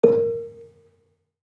Gambang-A#3-f.wav